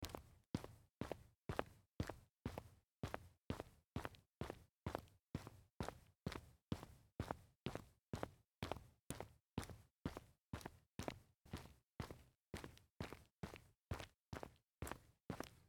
Звуки шагов, бега
На этой странице собраны разнообразные звуки шагов и бега человека по различным покрытиям: от звонких шагов по паркету до приглушенных звуков движения по ковру.
Ходьба по бетону